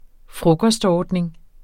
Udtale